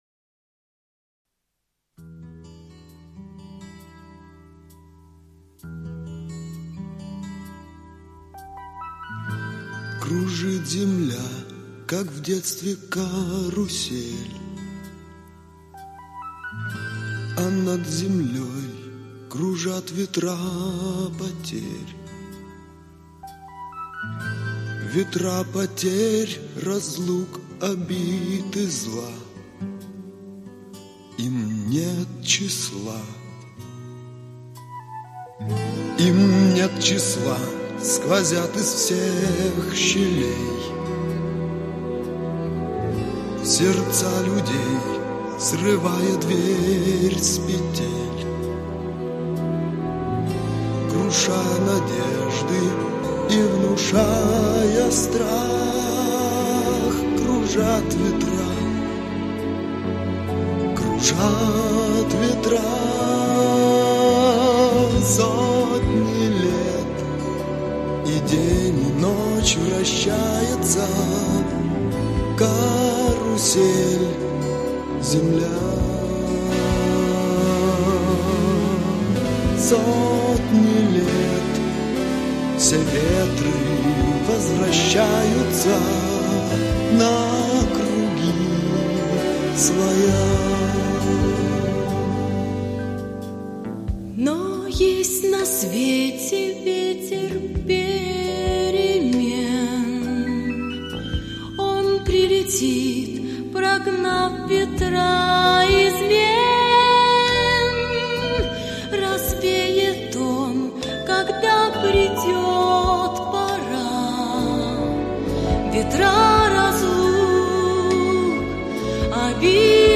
легкой мелодией, красивым текстом и нежным исполнением.